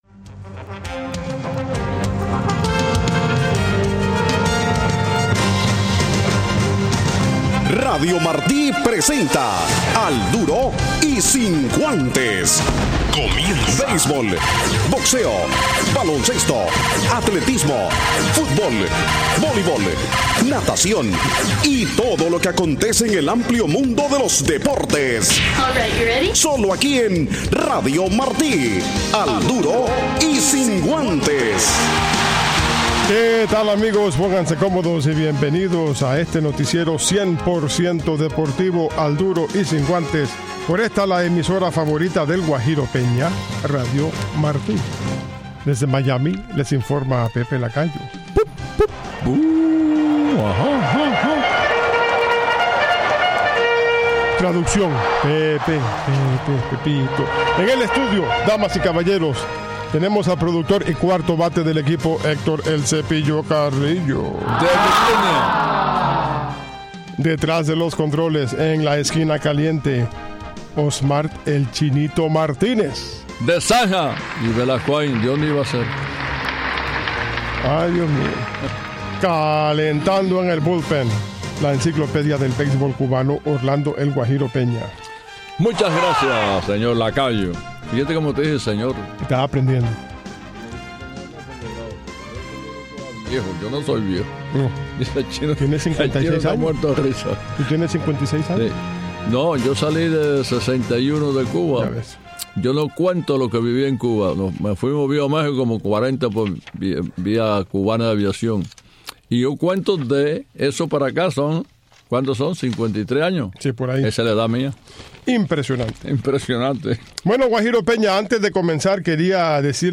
Al Duro del jueves con una entrevista de la Peña de Peña